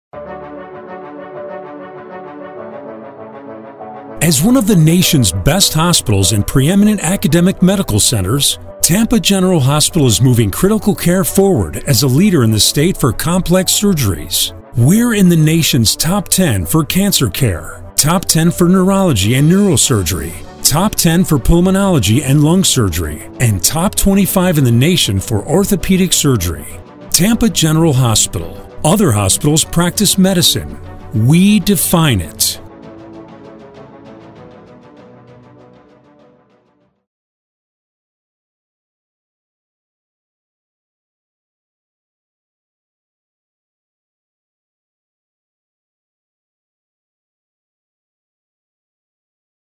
Midwest accent
Middle Aged
TampaMedical VO_.mp3